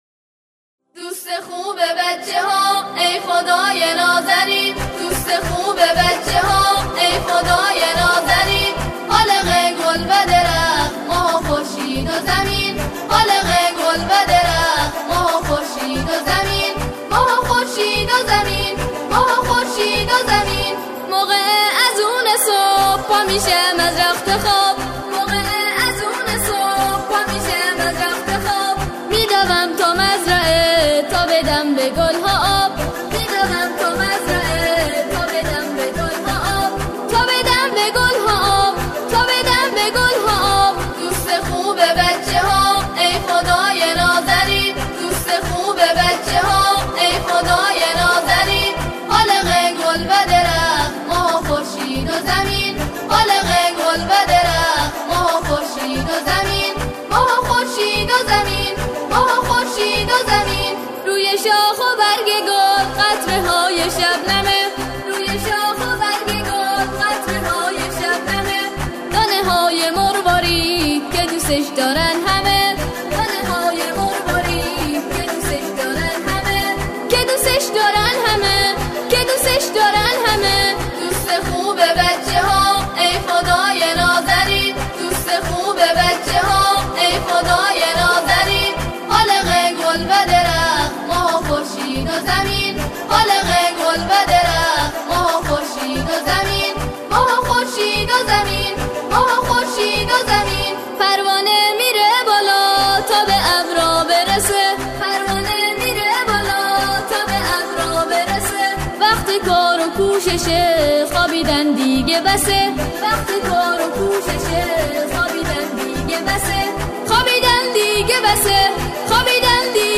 ترانه های کودکانه